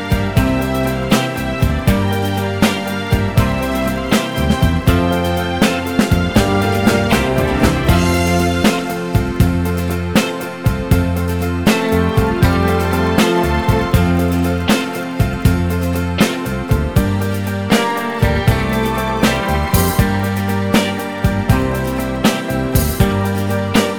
no Backing Vocals Duets 3:05 Buy £1.50